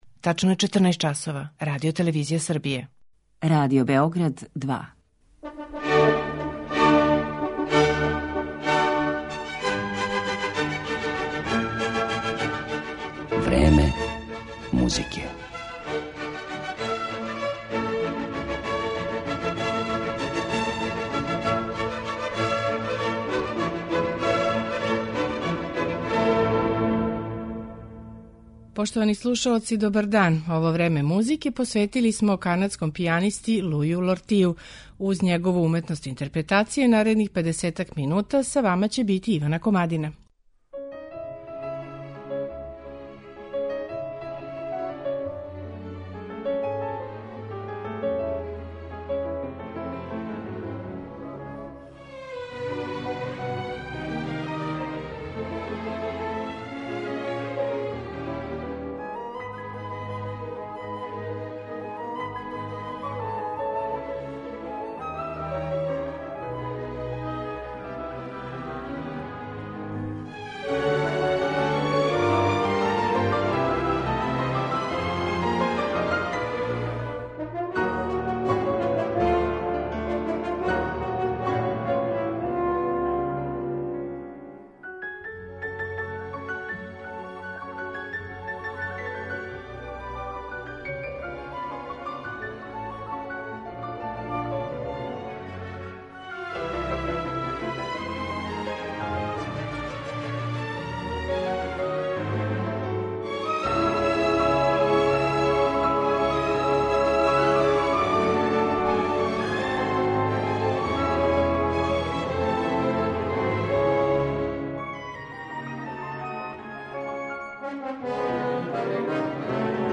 пијанистa